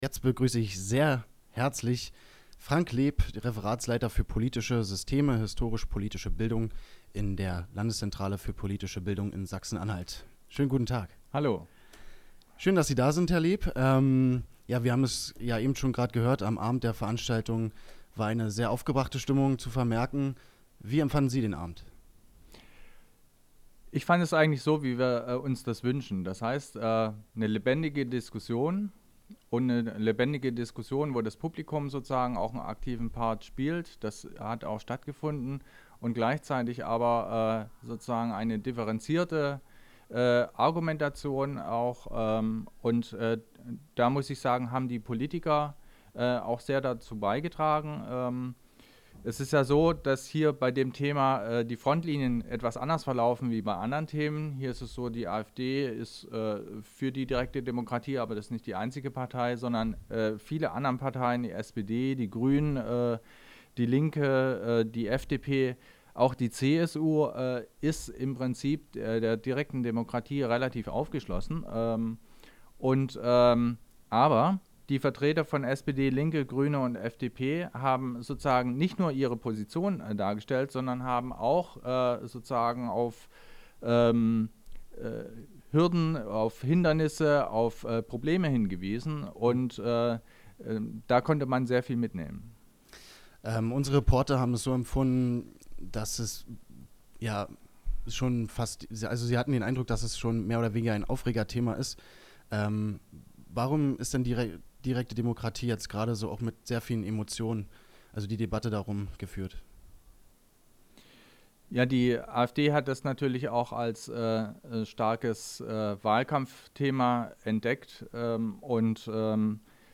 Das komplette Interview gibt es hier nachzuhören. https